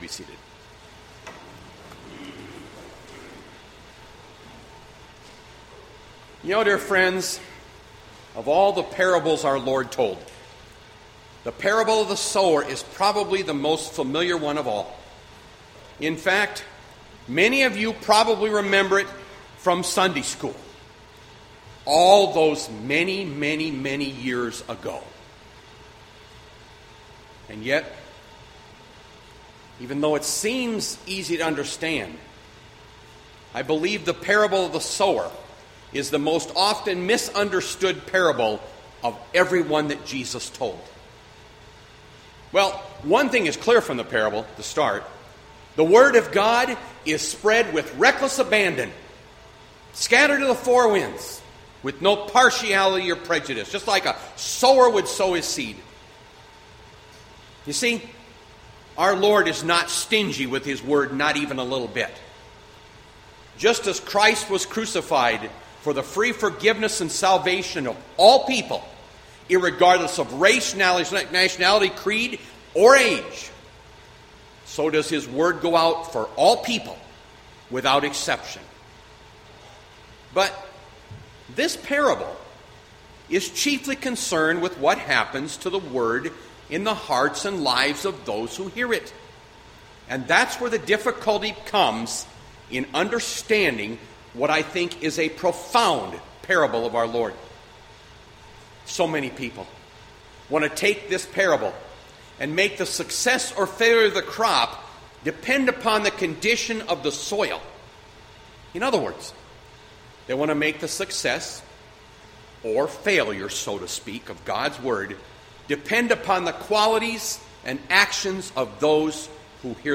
Bethlehem Lutheran Church, Mason City, Iowa - Sermon Archive Jul 12, 2020